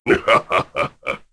Jin-Vox_Happy1_kr.wav